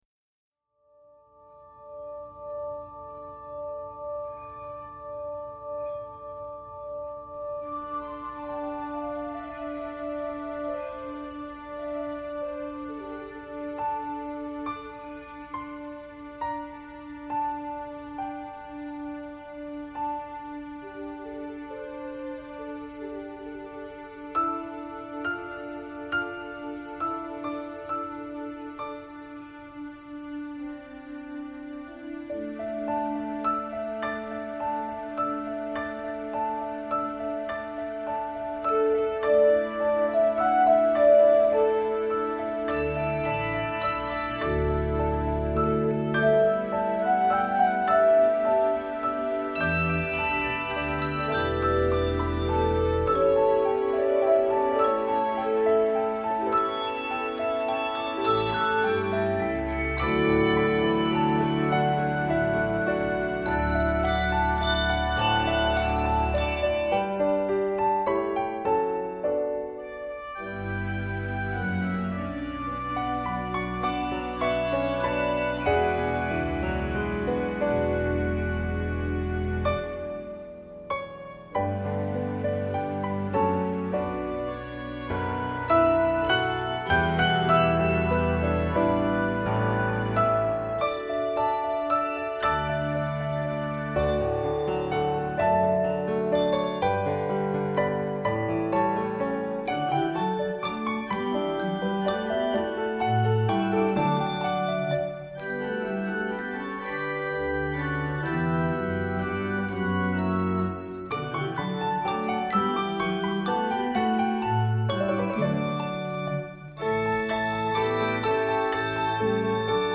this festive Easter prelude